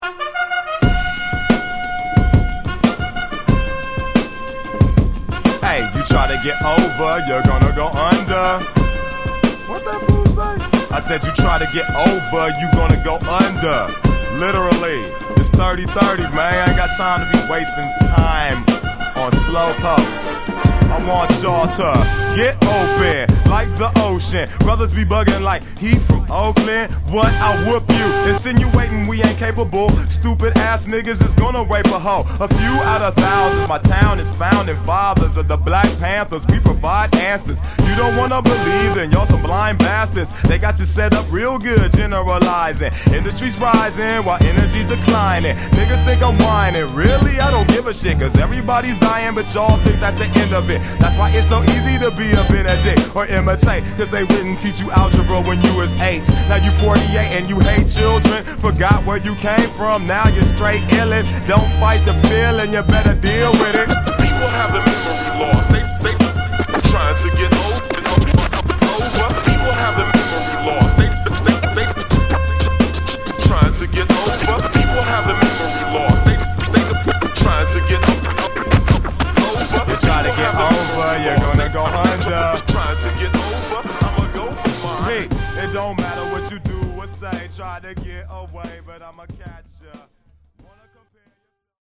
extremely potent vocals
lacing scratches and vocal samples throughout every song